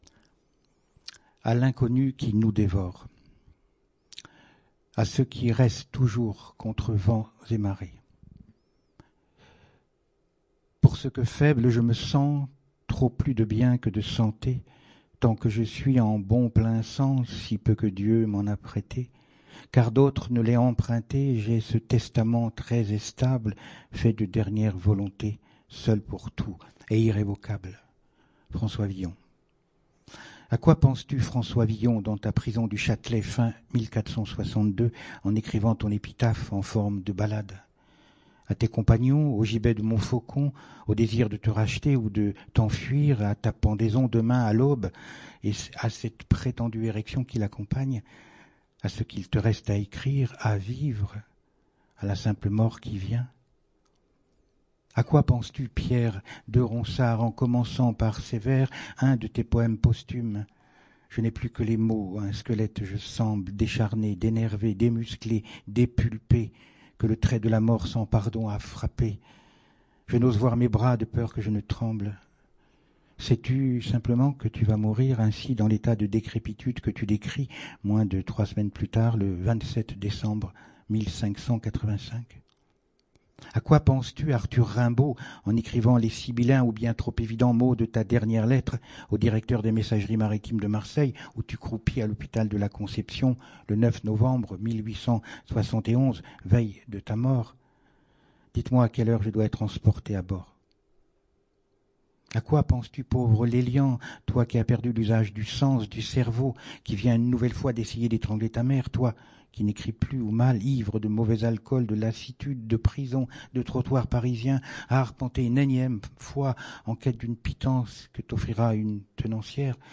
Saint-Malo, le 24 mai 2021.